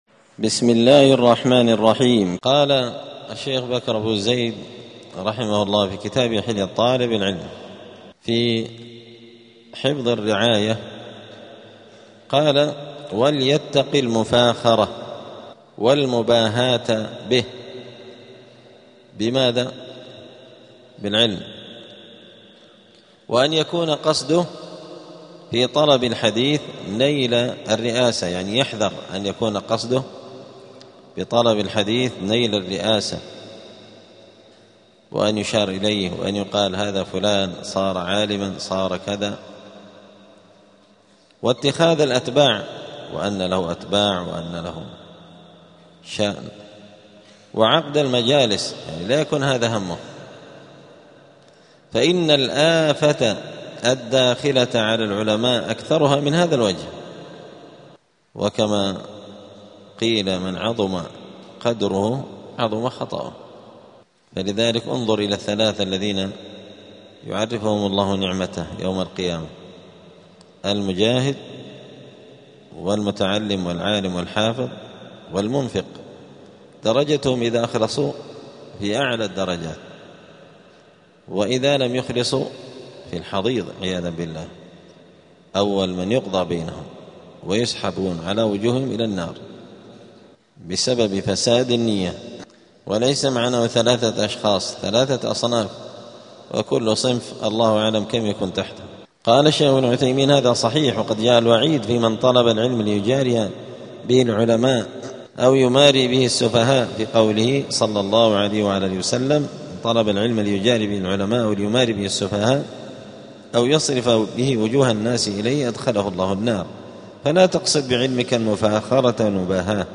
*الدرس الستون (60) فصل آداب الطالب في حياته العلمية {حفظ الرعاية}.*